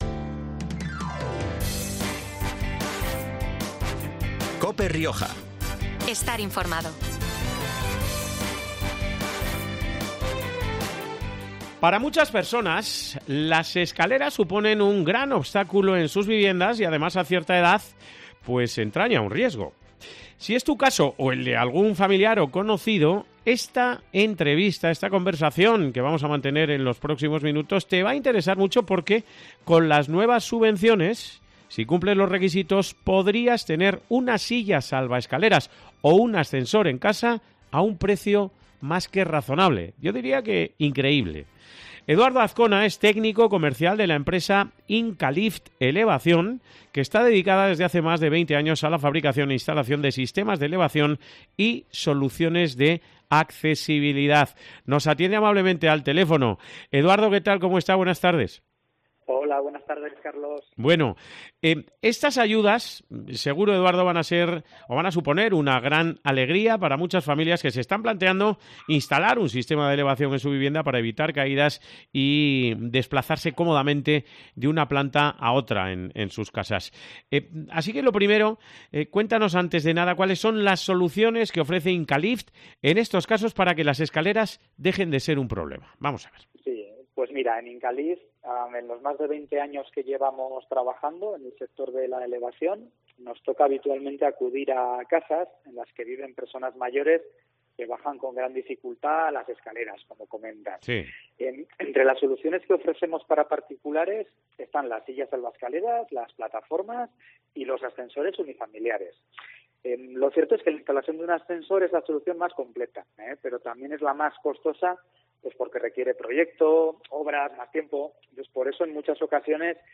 Si es tu caso o el de algún familiar, esta entrevista de hoy te va a interesar muchísimo porque con las nuevas subvenciones, si cumples los requisitos podrías tener una silla salvaescaleras o un ascensor en casa a un precio increíble.